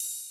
ride_cym2.ogg